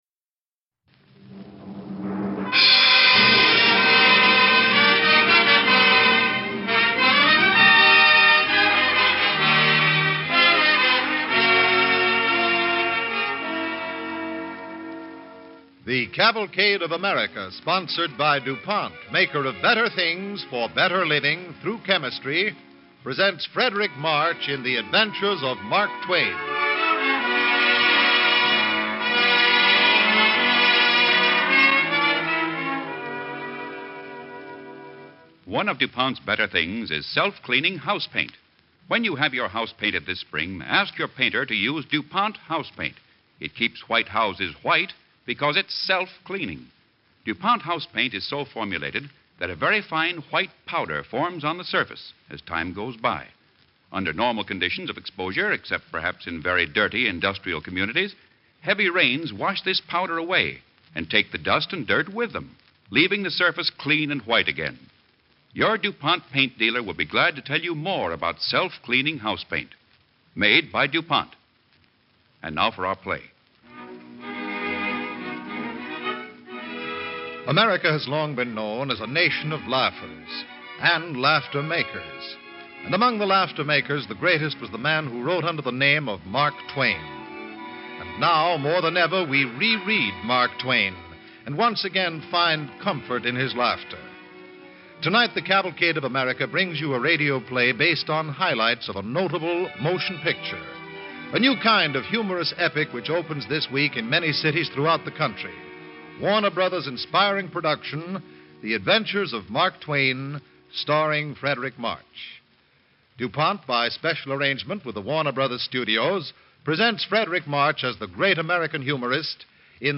The Adventures of Mark Twain, starring Fredric March and Roland Winters